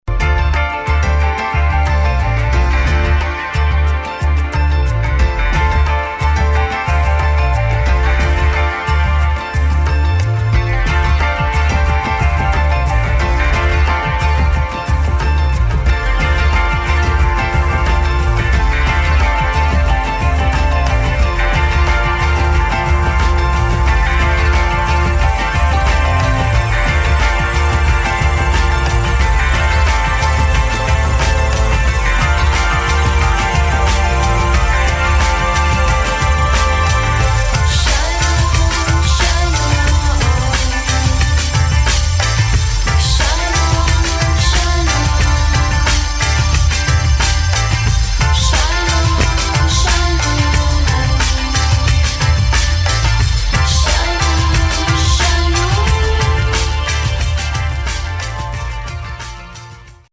[ POP / ROCK / INDIE ]